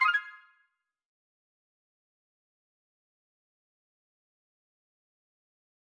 obsydianx-interface-sfx-pack-1
confirm_style_4_003.wav